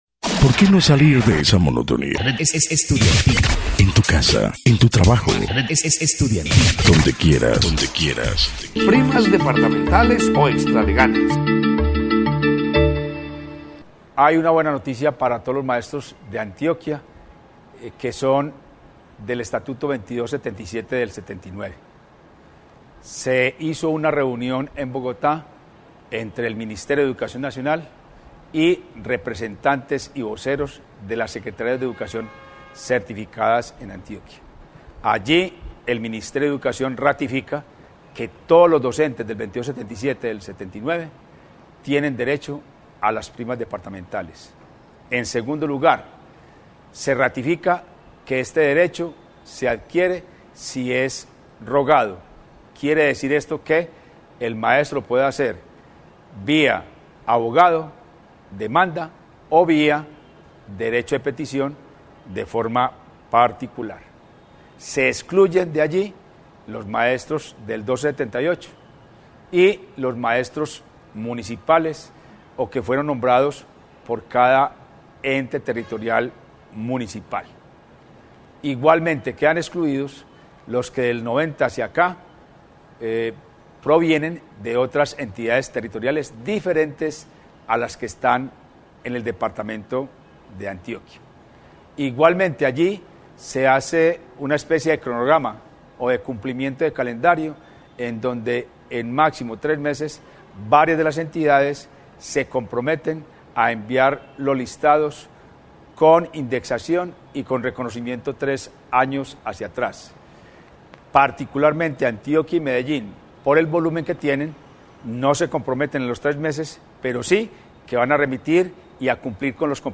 Tomado del programa de televisión de Adida ( Despertar educativo) del dia 22 de marzo.